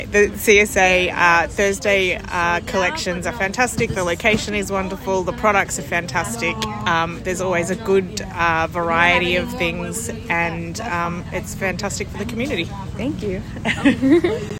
Listen to testimonials from our community members.